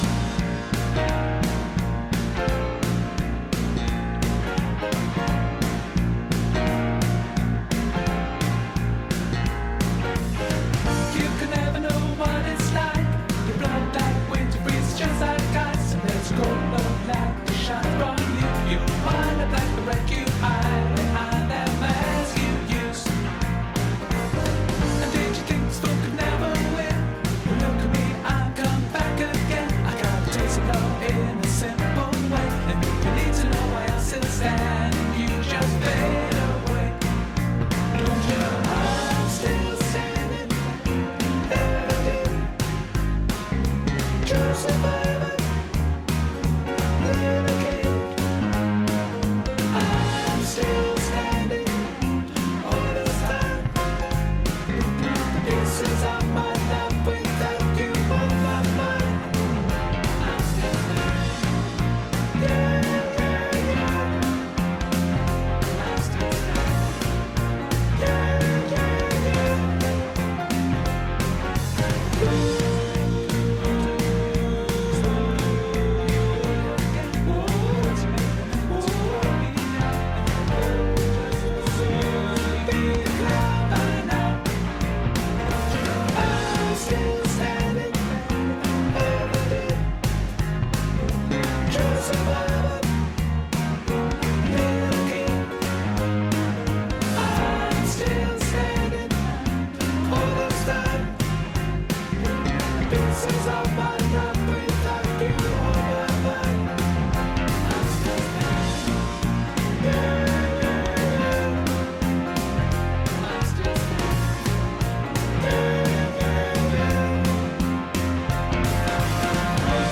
I’m Sill Standing Soprano Backing Track | Ipswich Hospital Community Choir